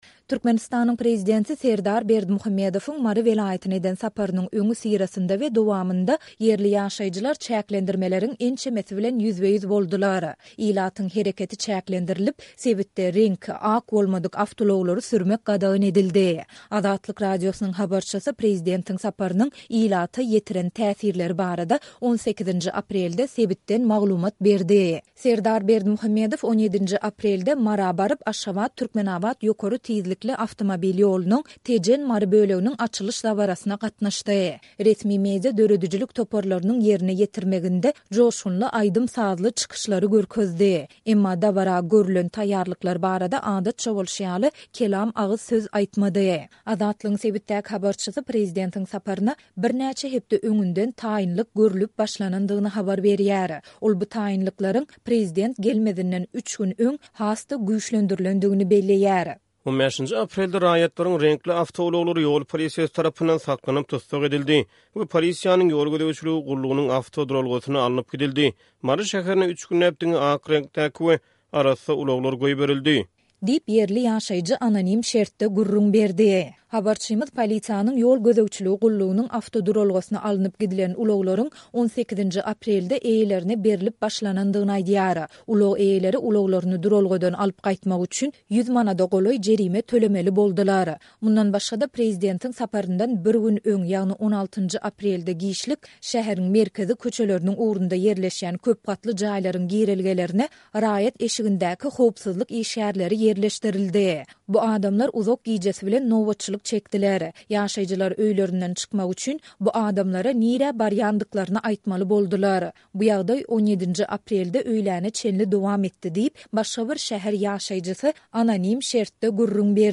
Türkmenistanyň prezidenti Serdar Berdimuhamedowyň Mary welaýatyna eden saparynyň öňüsyrasynda we dowamynda ýerli ýaşaýjylar çäklendirmeleriň ençemesi bilen ýüzbe-ýüz boldular. Azatlyk Radiosynyň habarçysy prezidentiň saparynyň ilata ýetiren täsirleri barada 18-nji aprelde sebitden maglumat berdi.